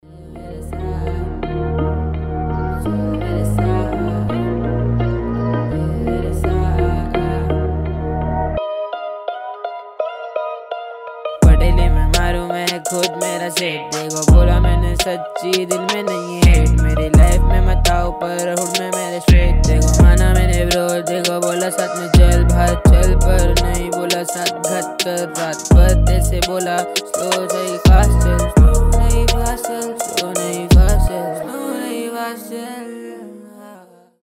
рэп
мелодичные
хип-хоп